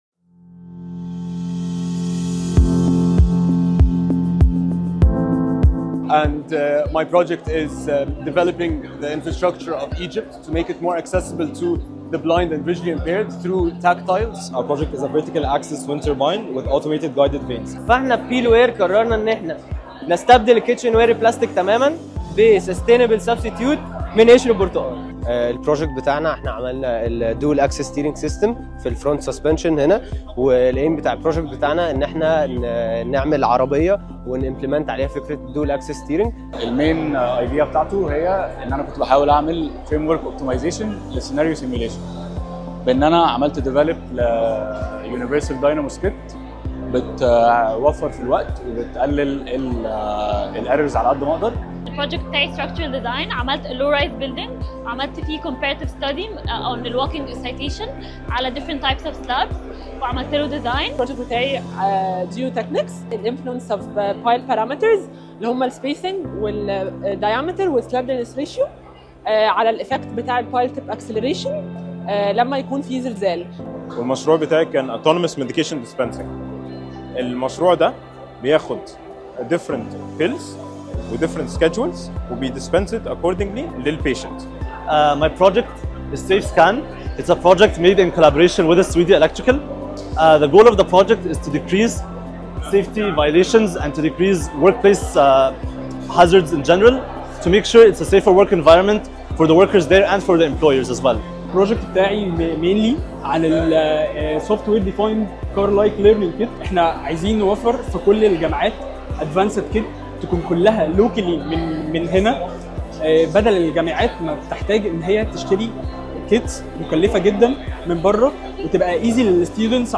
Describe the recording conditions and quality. Our Engineering students showcased their innovation and technical skills at the 3rd Annual Engineering Expo.